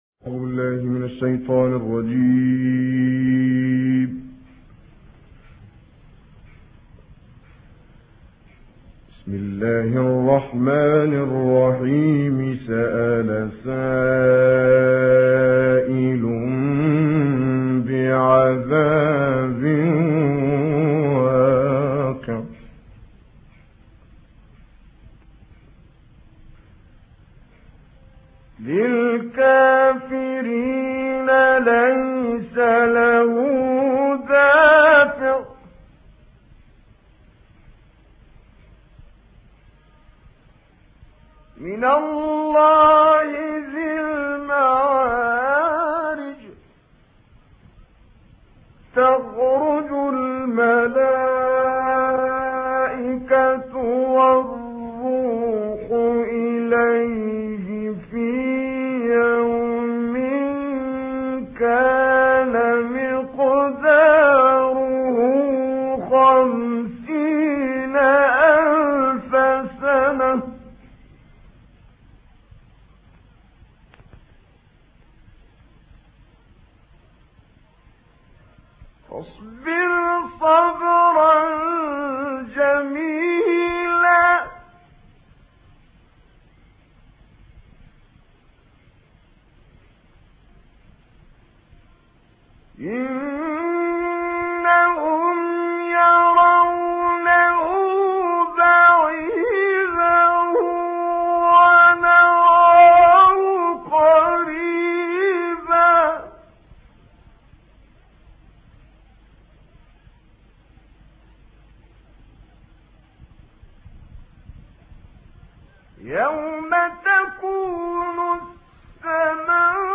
Quran recitations
Reciter Kamel Yousf El Behteemy